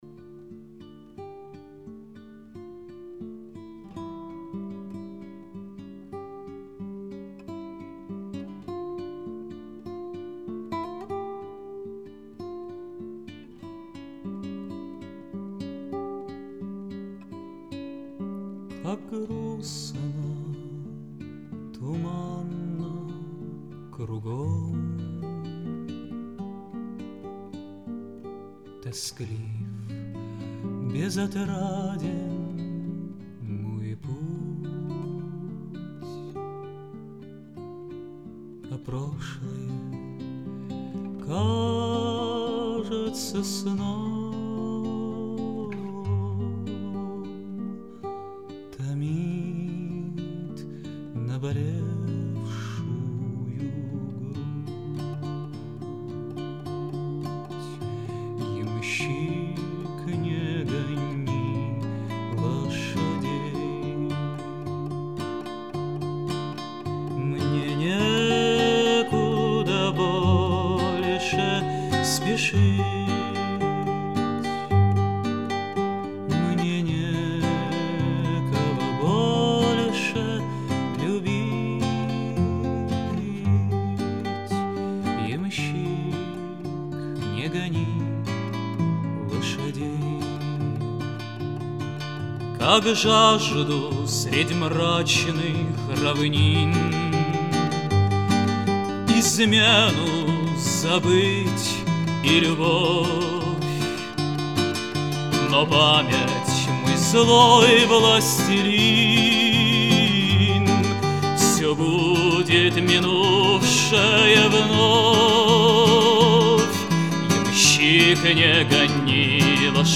В джазовом сопровождении